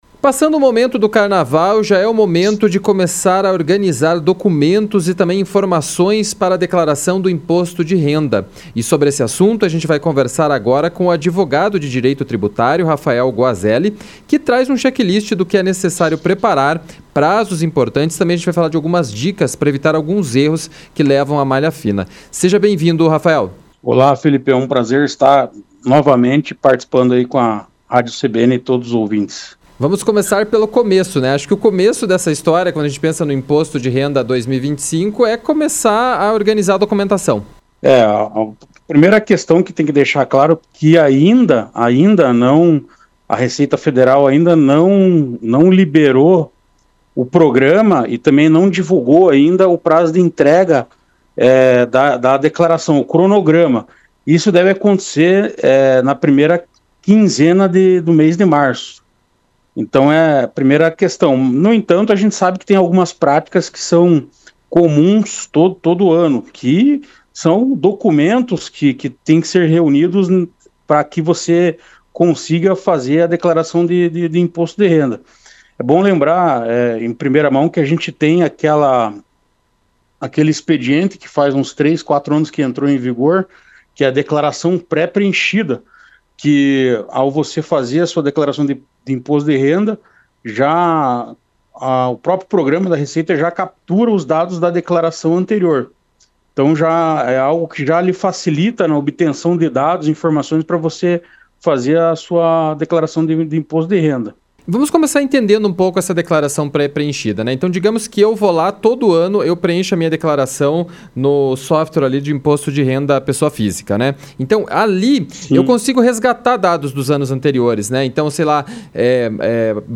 ENTREVISTA-IMPOSTO-DE-RENDA.mp3